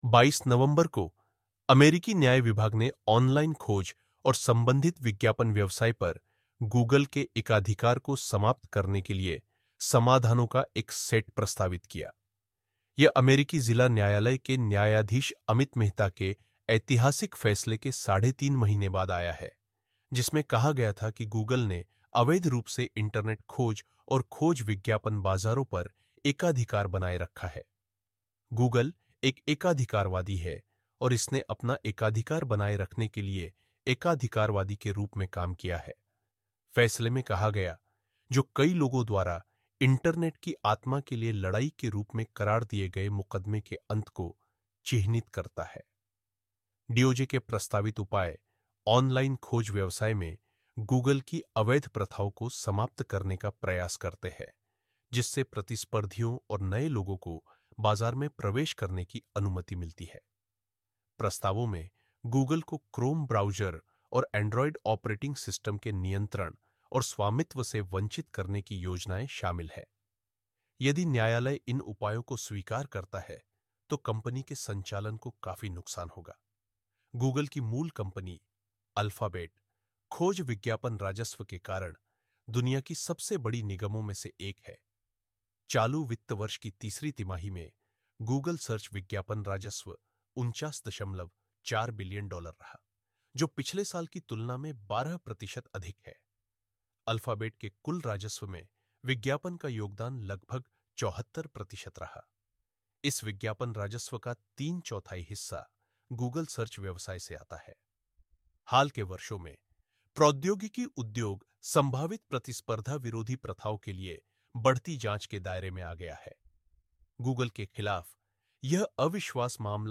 नीचे दिए गए इस लेख को सुनें: 22 नवंबर को, अमेरिकी न्याय विभाग ने ऑनलाइन खोज और संबंधित विज्ञापन व्यवसाय पर Google के एकाधिकार को समाप्त करने के लिए समाधानों का एक सेट प्रस्तावित किया।